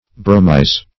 Search Result for " bromize" : The Collaborative International Dictionary of English v.0.48: Bromize \Bro"mize\, v. t. (Photog.)